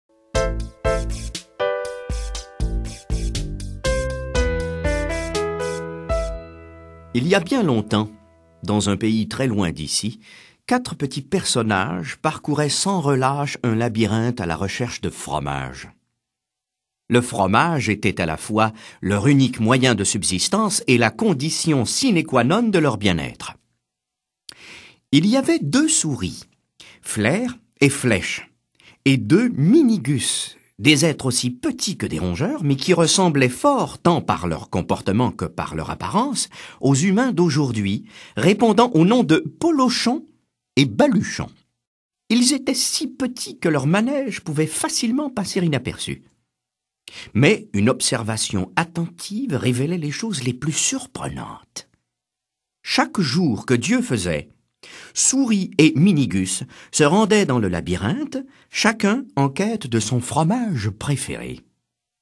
Click for an excerpt - Qui a piqué mon fromage ? de Spencer Johnson